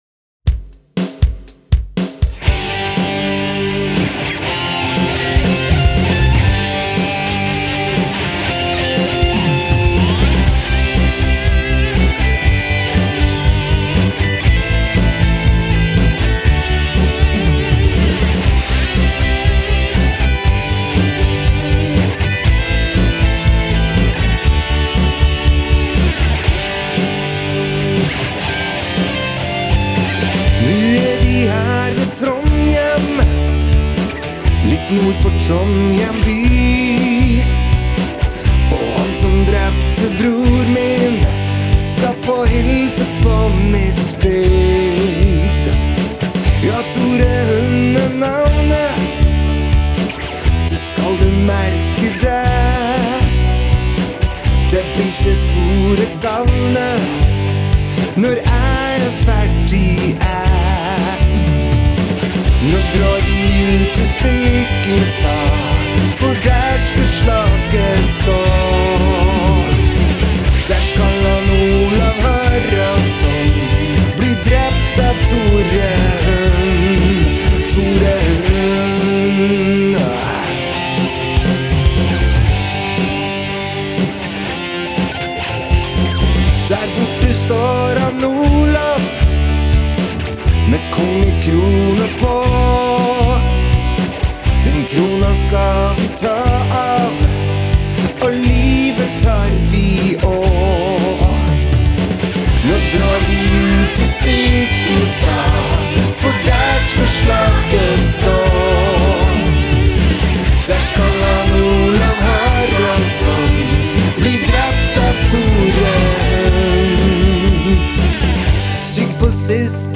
Kule gitarer og ganske rocka saker.